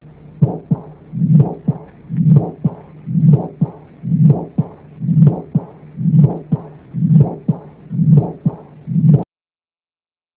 This Page contains some of the sounds of pathological Mitral and Aortic Valve Lesions.
AI and Long DM and SM and Attenuated S2 Plus AUSTIN FLINT Murmur